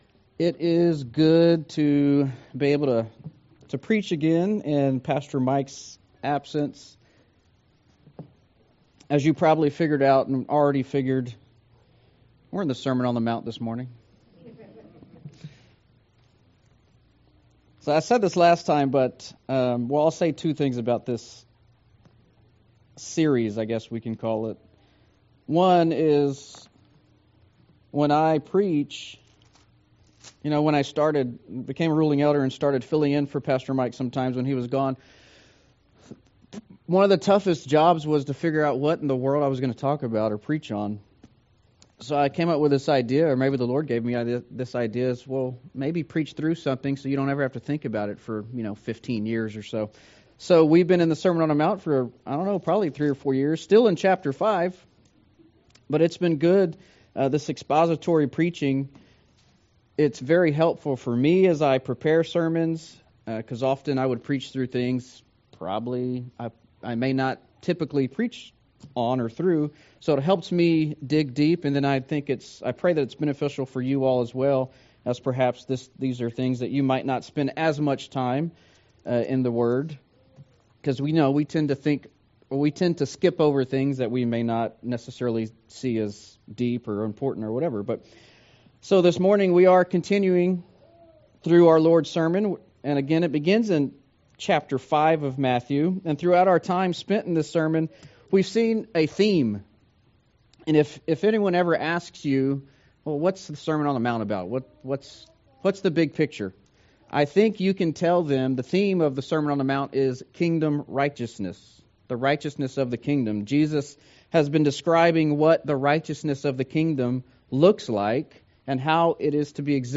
Sermon on the Mount Passage: Matthew 5:33-37 « The Fear of the Lord The Righteousness of the Kingdom